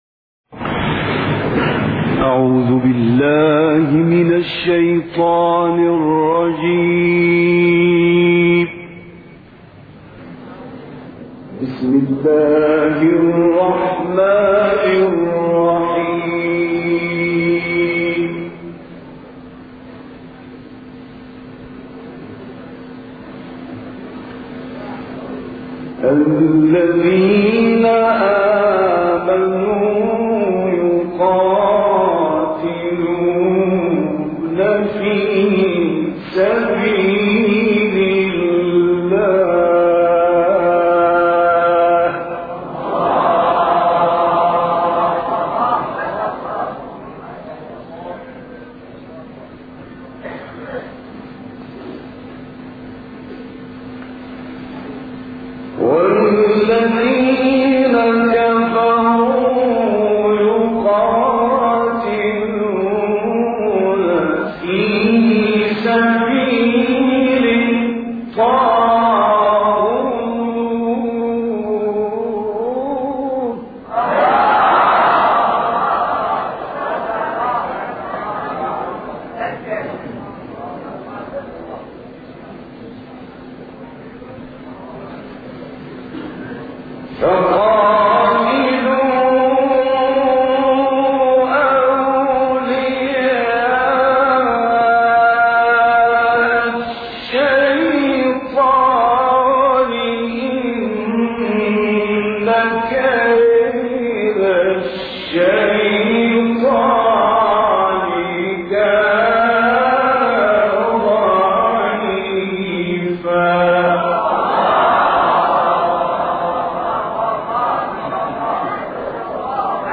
گروه فعالیت‌های قرآنی: قطعه‌ای از تلاوت مرحوم راغب مصطفی غلوش، قاری مصری از آیات 76 تا 81 سوره مبارکه نساء ارائه می‌شود.